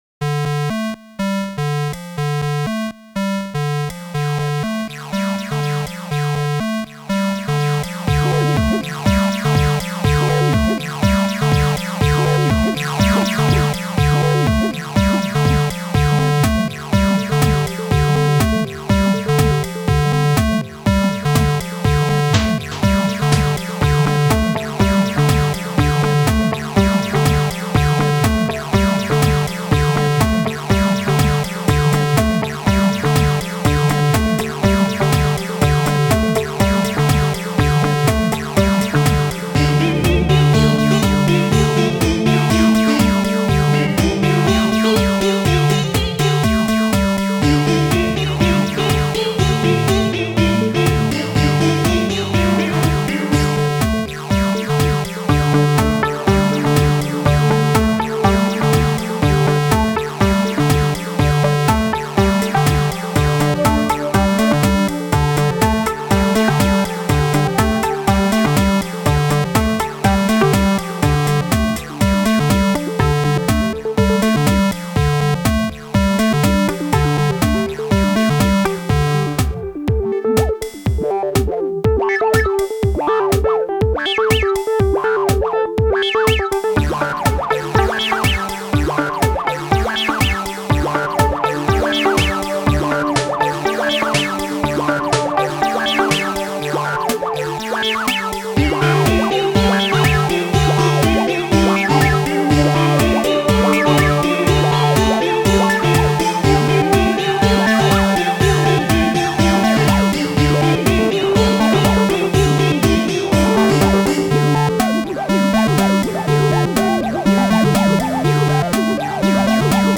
Genre: Arcade | Old School | Retro Gaming